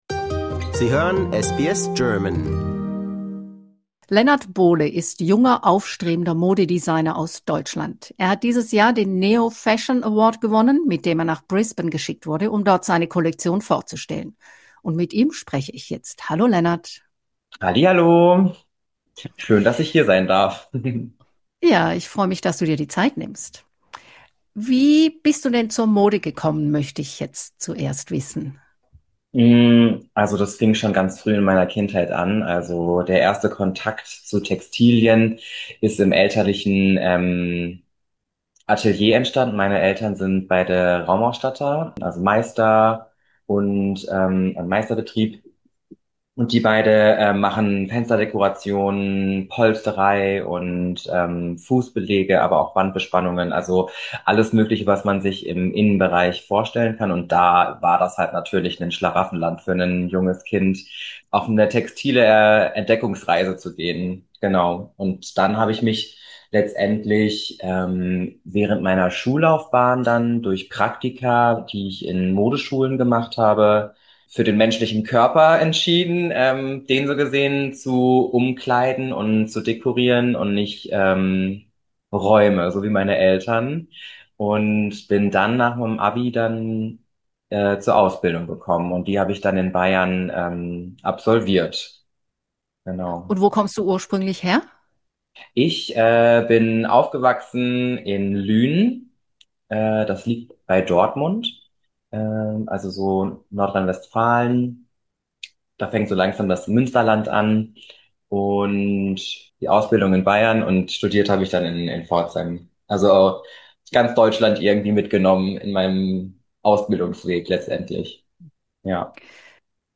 Credit: DeFrance Images Für mehr Geschichten, Interviews und Nachrichten von SBS German, entdecken Sie hier unsere Podcast-Sammlung.